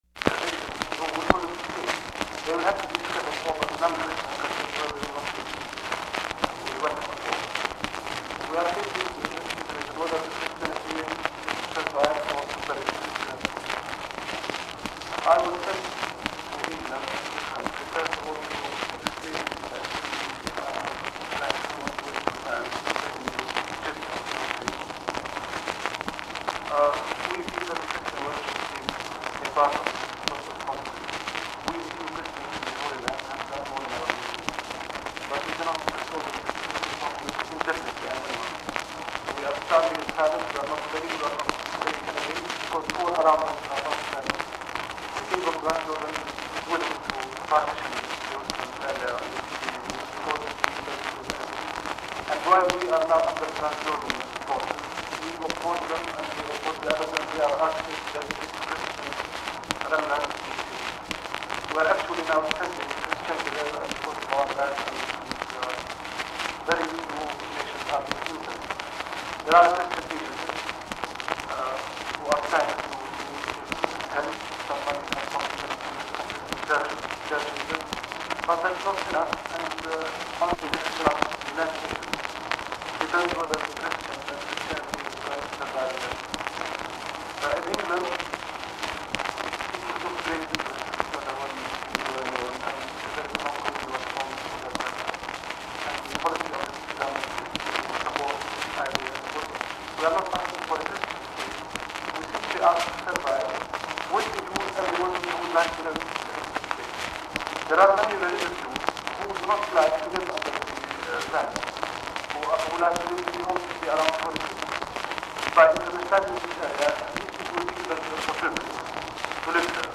The recording begins with the conversation already in progress.
Secret White House Tapes